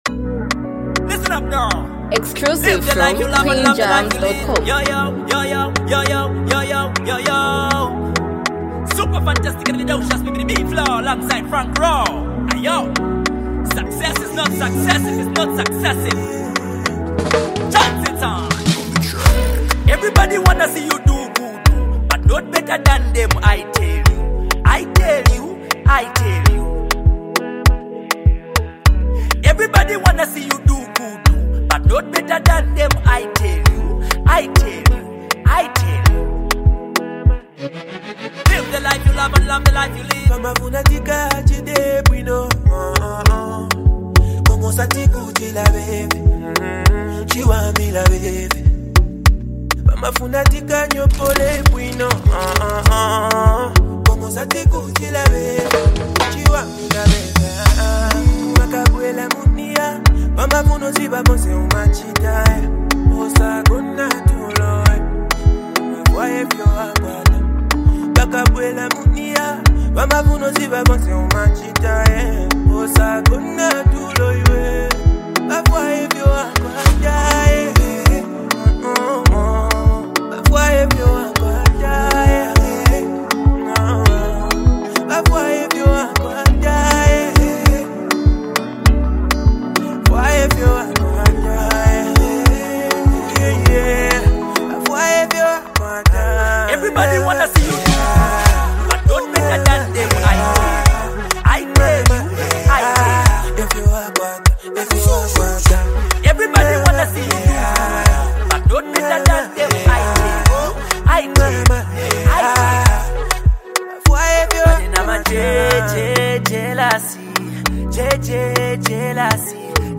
hype and a powerful
unique rap flow